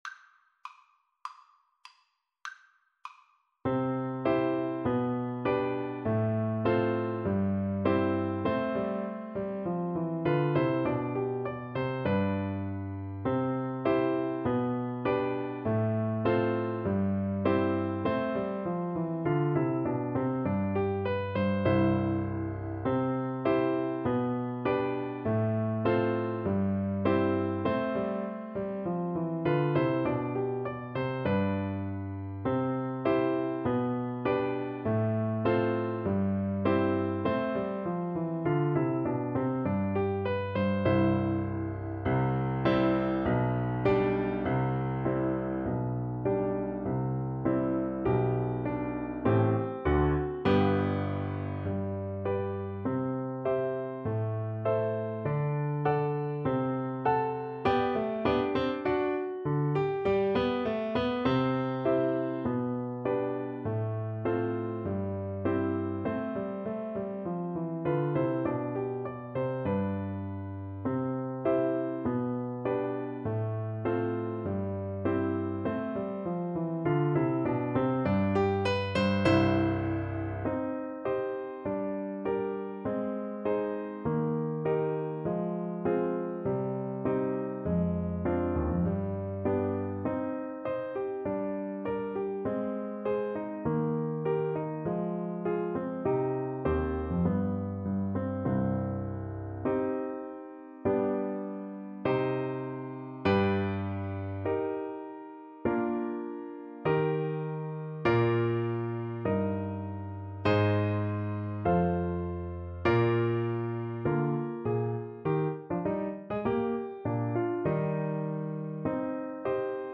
Play (or use space bar on your keyboard) Pause Music Playalong - Piano Accompaniment Playalong Band Accompaniment not yet available transpose reset tempo print settings full screen
C major (Sounding Pitch) D major (Clarinet in Bb) (View more C major Music for Clarinet )
~ = 100 Allegretto
Classical (View more Classical Clarinet Music)